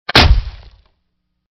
woodshieldbash.ogg